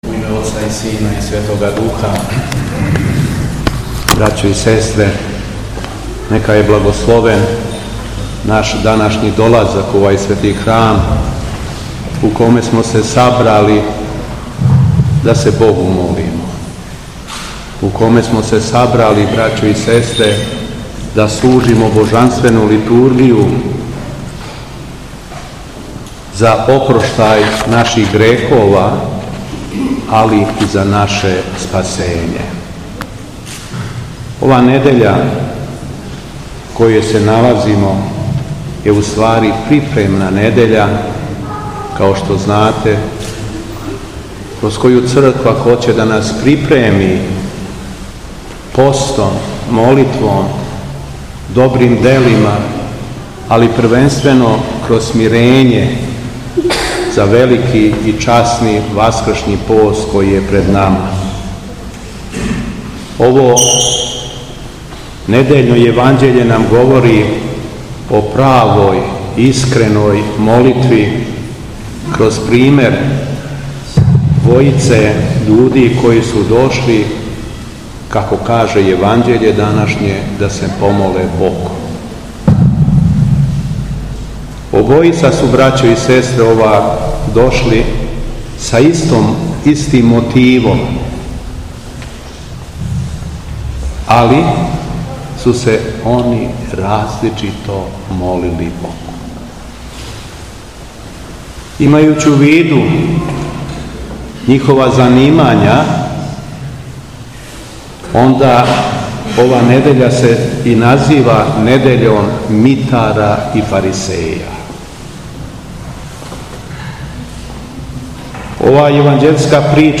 У недељу о митару и фарисеју, 25. фебруара 2024. године, када наша Света Црква прославља и празнује Светог Мелетија Антиохијског, Његово Преосвештенство Епископ шумадијски Г. Јован служио је Свету Архијерејску Литургију у храму Успења Пресвете Богородице у Младеновцу.
Беседа Његовог Преосвештенства Епископа шумадијског г. Јована